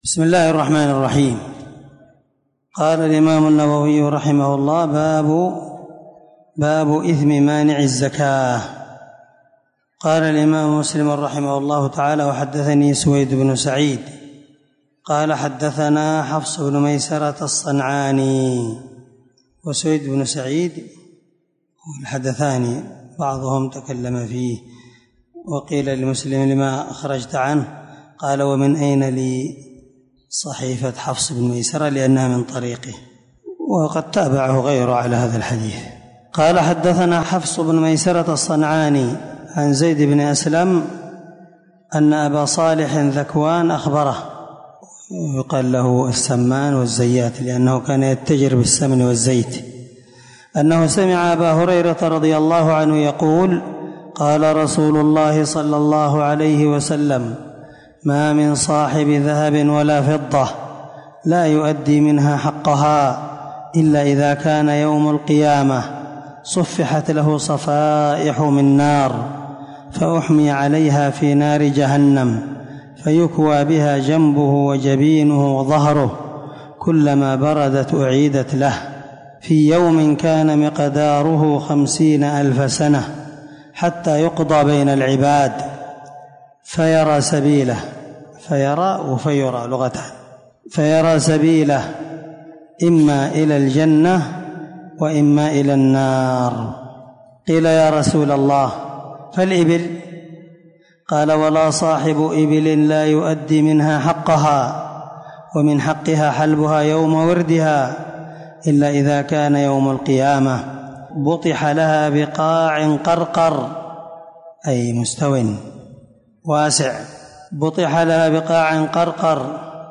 600الدرس 8من شرح كتاب الزكاة حديث رقم(987) من صحيح مسلم